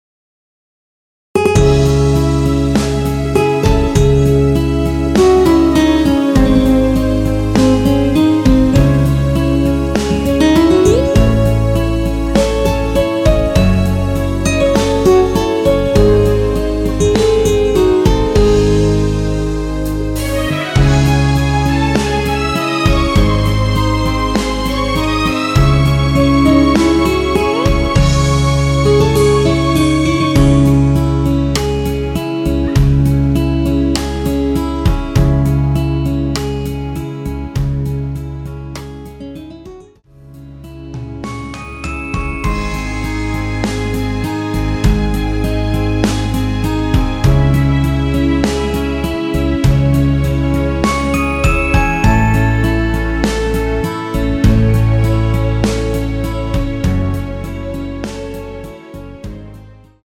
원키에서(-1)내린 MR입니다.
C#m
앞부분30초, 뒷부분30초씩 편집해서 올려 드리고 있습니다.
중간에 음이 끈어지고 다시 나오는 이유는